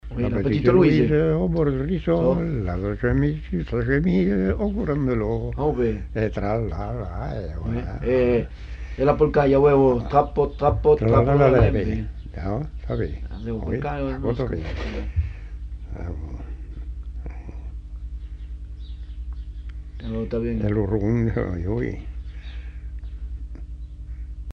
Lieu : Simorre
Genre : chant
Effectif : 1
Type de voix : voix d'homme
Production du son : chanté
Danse : scottish